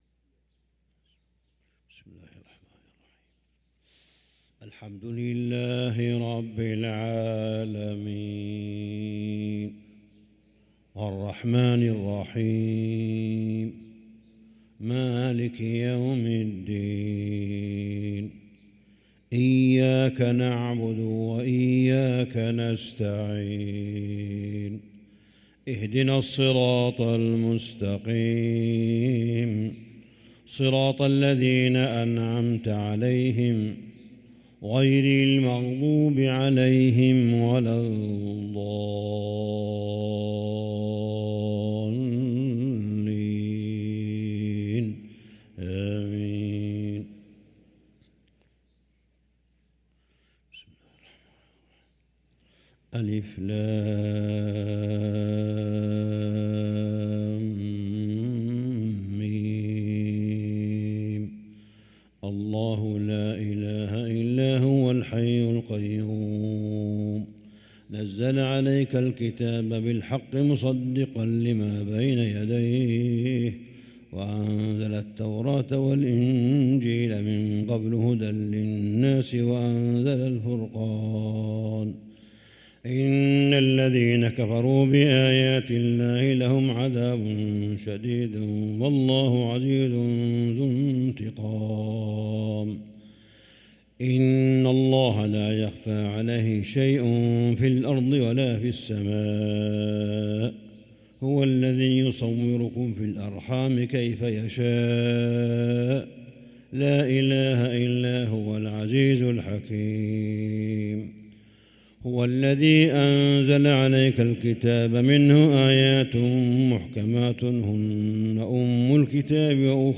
صلاة الفجر للقارئ صالح بن حميد 20 ربيع الآخر 1445 هـ